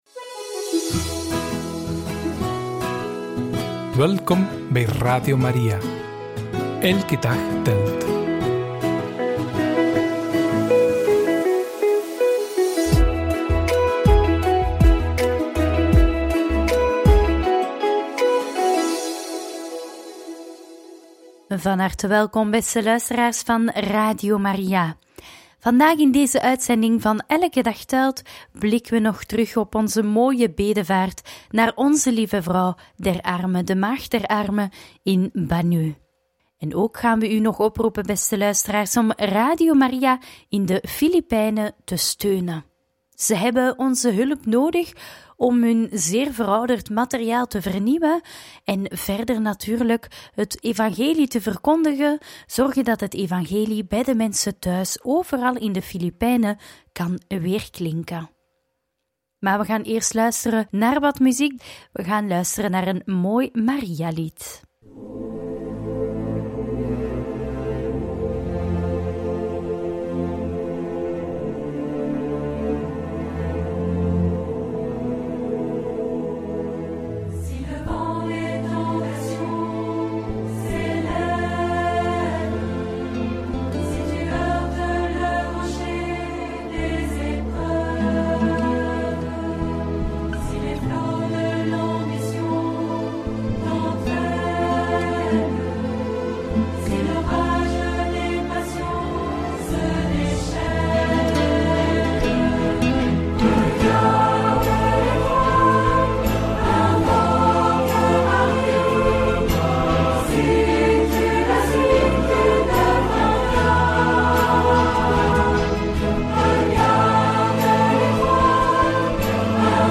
Gesprekken op onze bedevaart en we ontdekken verder het werk van Radio Maria in de Filipijnen – Radio Maria